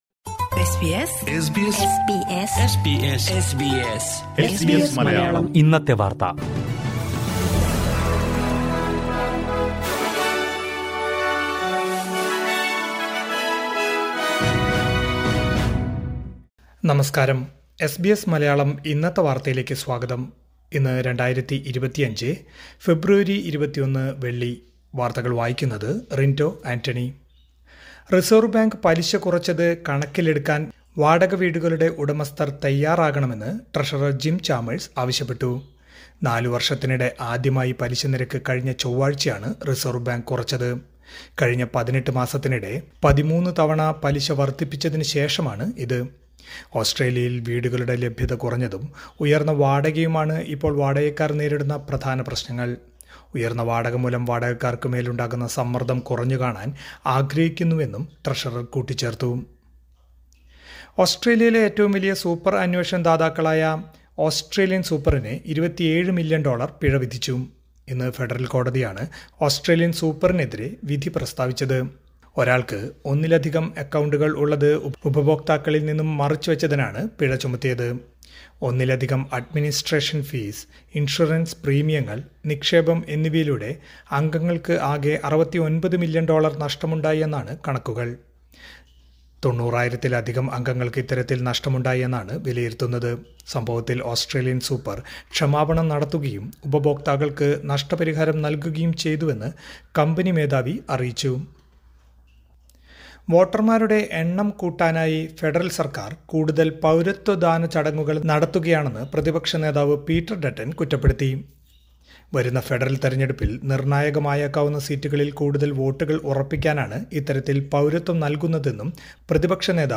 2025 ഫെബ്രുവരി 21ലെ ഓസ്‌ട്രേലിയയിലെ ഏറ്റവും പ്രധാന വാര്‍ത്തകള്‍ കേള്‍ക്കാം...